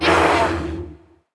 Index of /App/sound/monster/spite_ghost